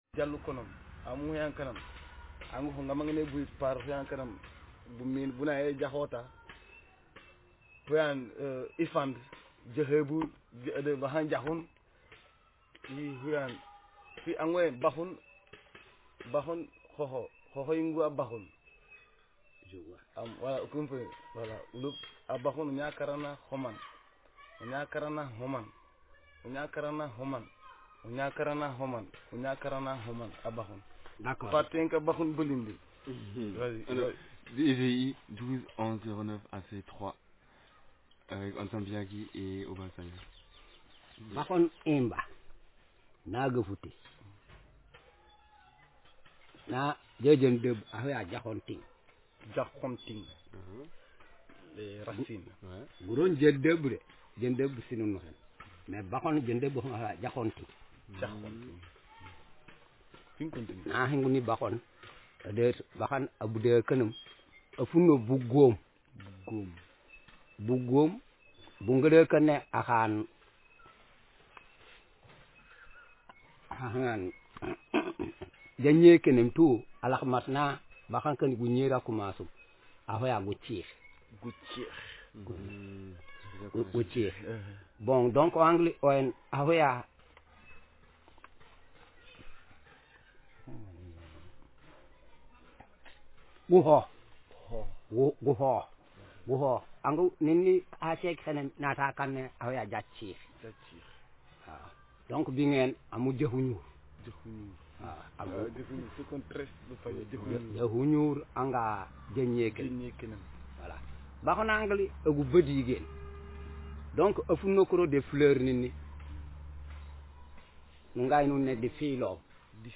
Speaker sexm/m
Text genreconversation